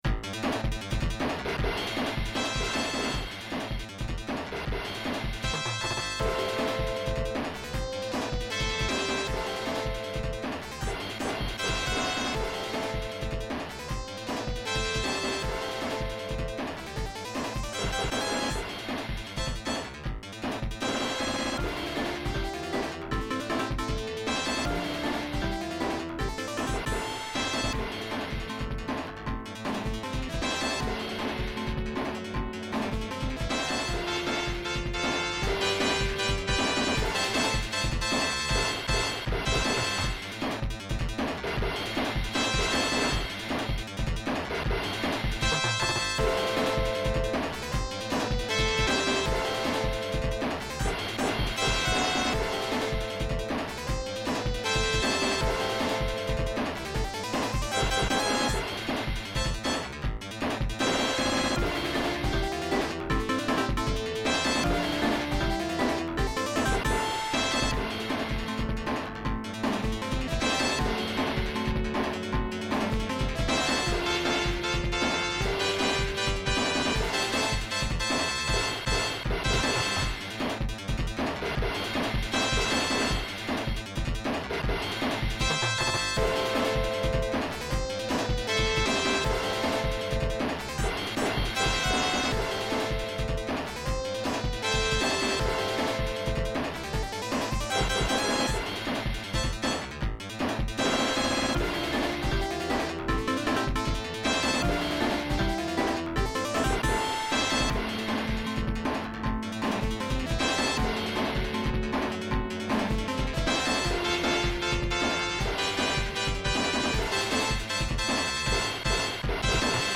All of this music is from the arcade version of the game.